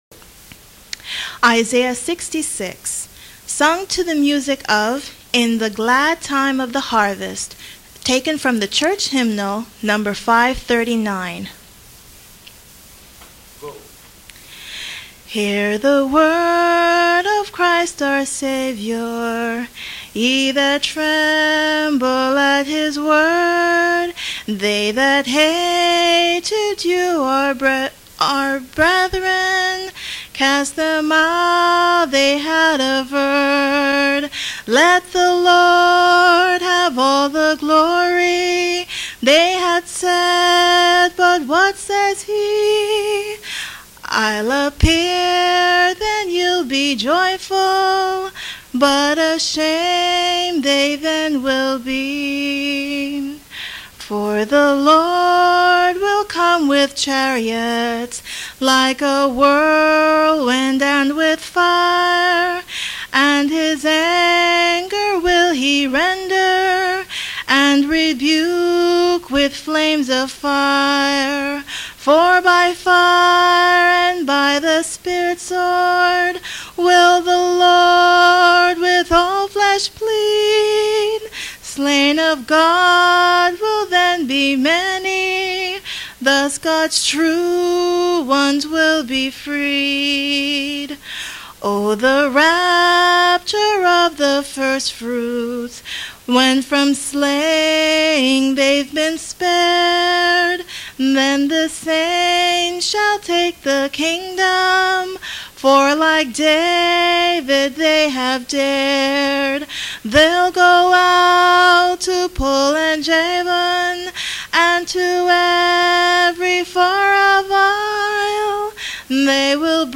Sung without instrumental accompaniment